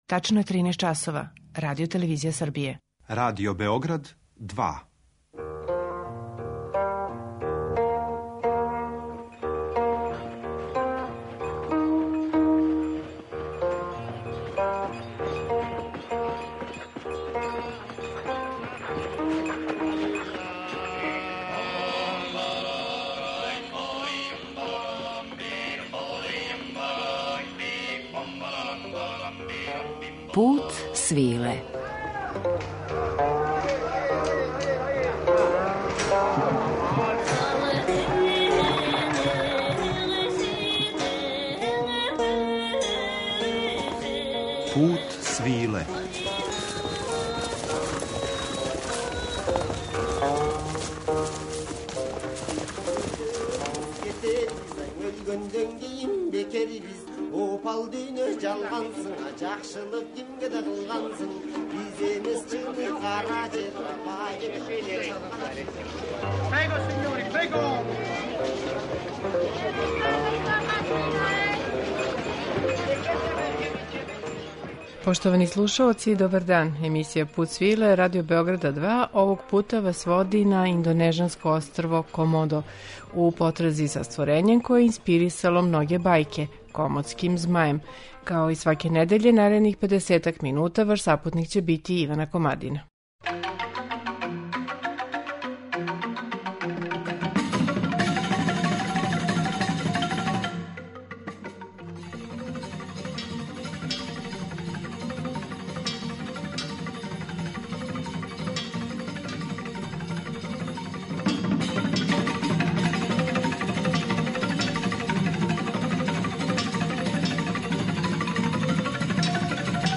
Обићи ћемо острво Комодо које изгледа као живи парк из доба Јуре, у музичком друштву индонежанских уметника са Јаве, Балија и Ломбока
Пут свиле, као јединствено “радијско путовање”, недељом одводи слушаоце у неку од земаља повезаних са традиционалним Путем свиле, уз актуелна остварења из жанра “World music” и раритетне записе традиционалне музике.